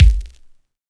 Dilla Kick 60.wav